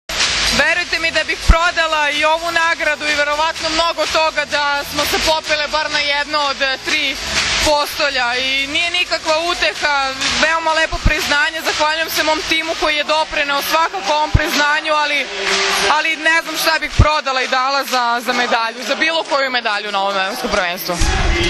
IZJAVA JOVANE BRAKOČEVIĆ